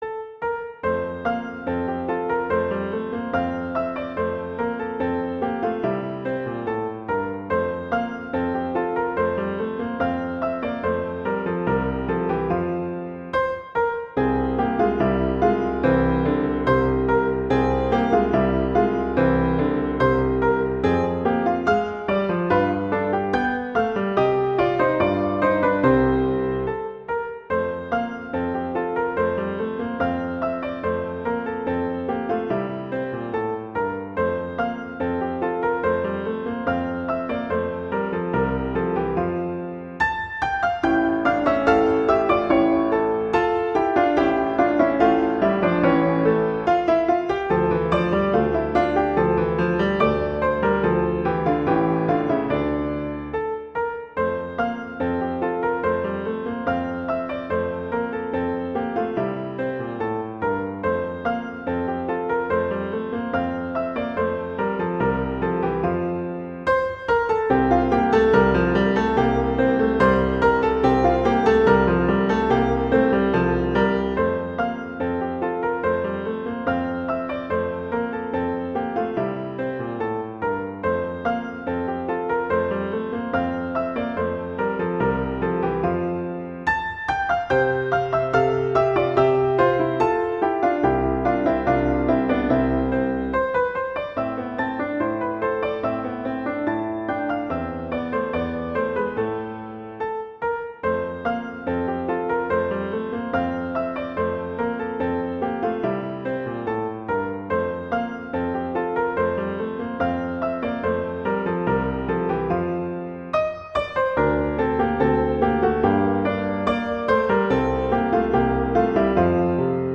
classical
F major
♩=144 BPM